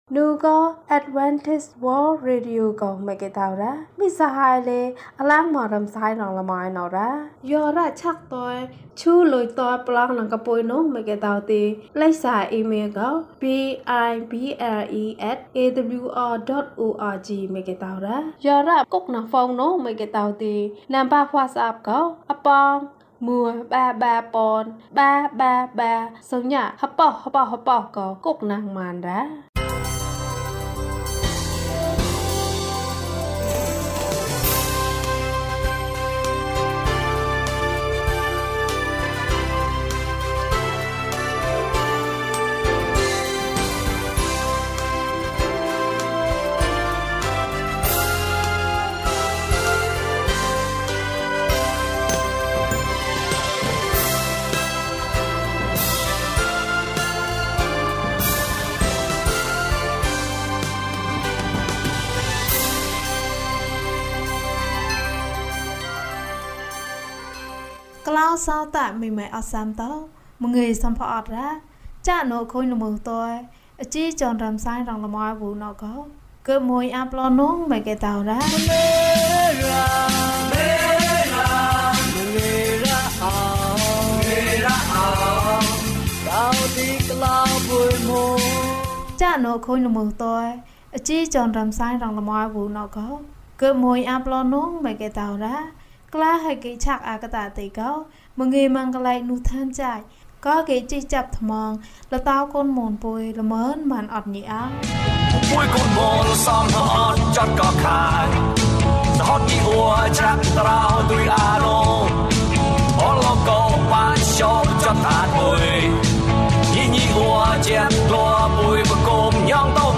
မိသားစုနှင့်အတူ ဘုရားသခင်။၀၂ ကျန်းမာခြင်းအကြောင်းအရာ။ ဓမ္မသီချင်း။ တရားဒေသနာ။